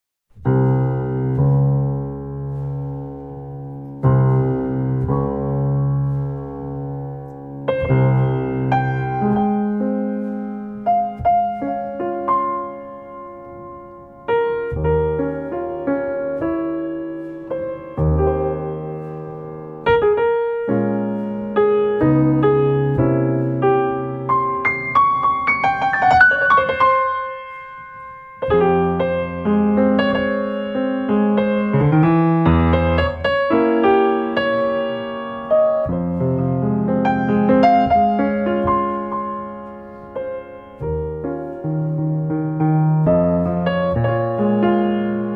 Compilation Jazz Album